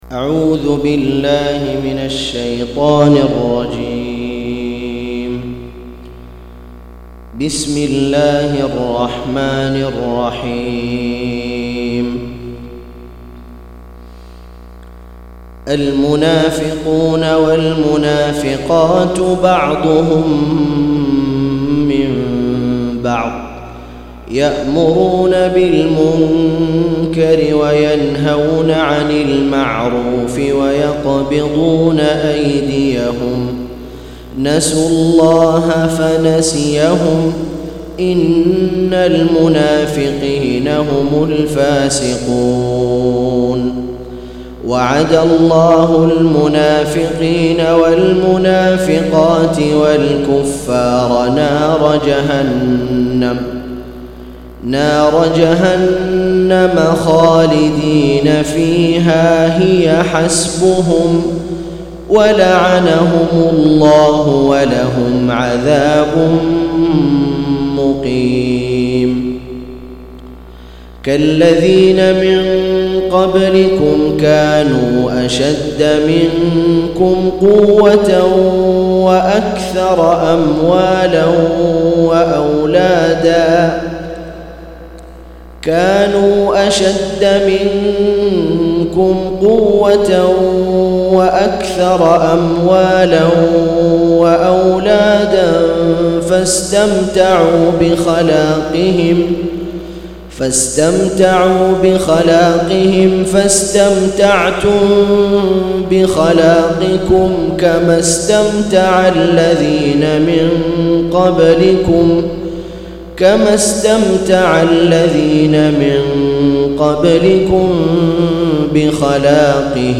187- عمدة التفسير عن الحافظ ابن كثير رحمه الله للعلامة أحمد شاكر رحمه الله – قراءة وتعليق –